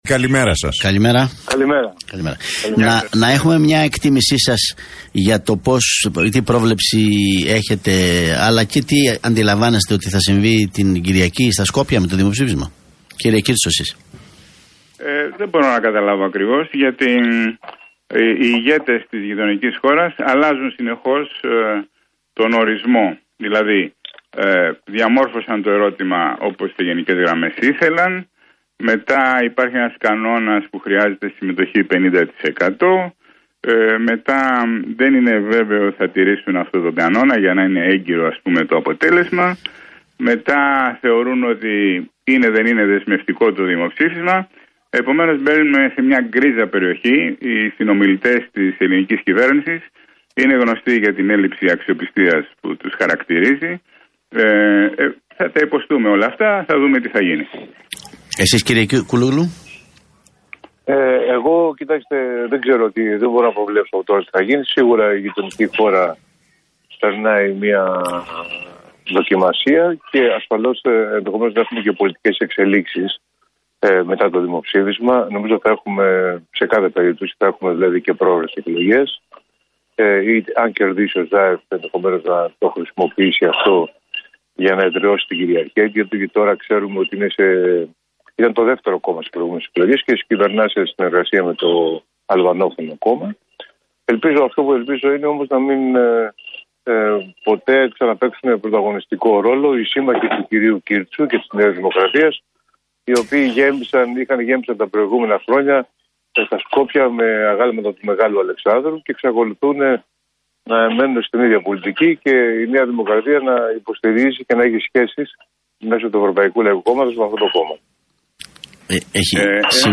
Τα ξίφη τους διασταύρωσαν στον αέρα του realfm 97,8 και την εκπομπή του Νίκου Χατζηνικολάου, ο ευρωβουλευτής του ΣΥΡΙΖΑ, Στέλιος Κούλογλου και ο ευρωβουλευτής της ΝΔ, Γιώργος Κύρτσος.